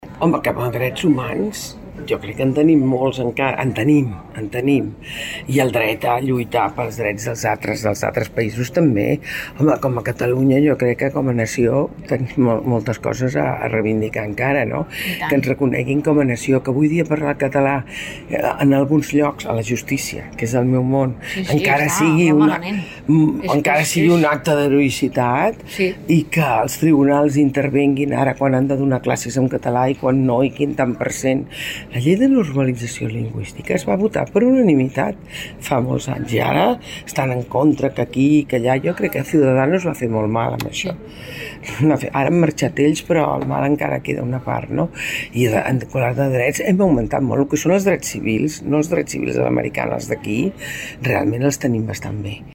Magda Oranich, advocada i autora 'Totes les batalles'